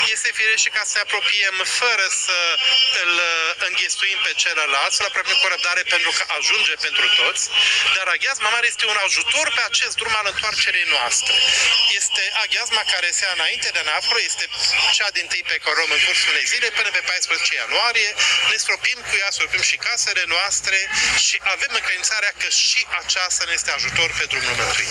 Aproximativ 800 de credincioși au participat la Sfânta Liturghie prilejuită de sărbătoarea Botezului Domnului, la Catedrala Mitropolitană din Iași. Slujba a fost oficiată de Preasfințitul Nichifor Botoșăneanul, alături de un sobor de preoți și diaconi.